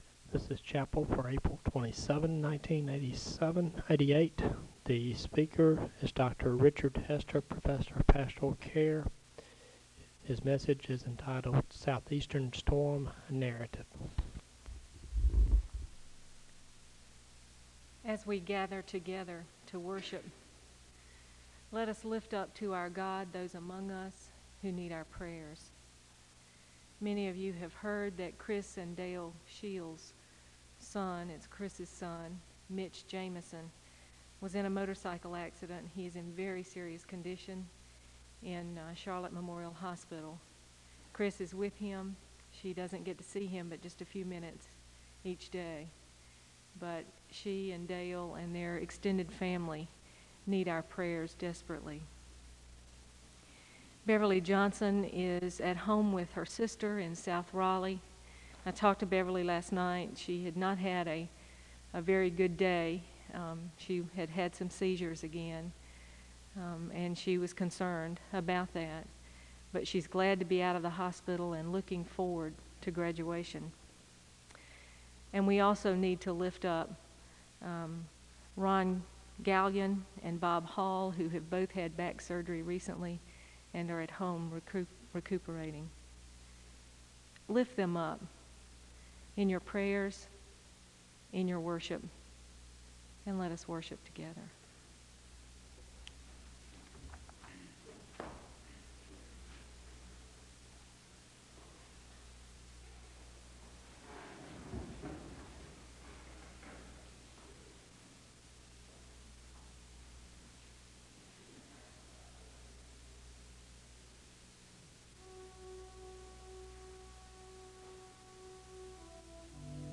The service begins with the sharing of prayer concerns (0:00-1:43). The choir sings a song of worship (1:44-8:14). There is a moment of prayer (8:15-9:59).